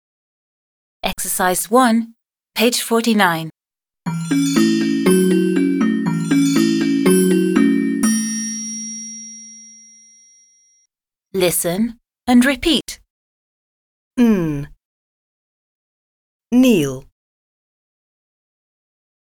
1. Listen and repeat the sound and the word. – Слушайте и повторяйте звук и слово.